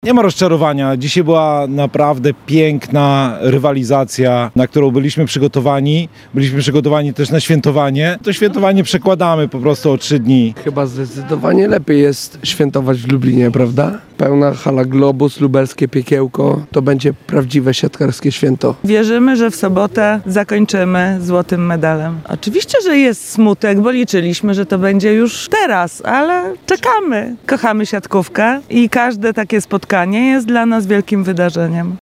– Świętowanie odkładamy do soboty – mówią kibice.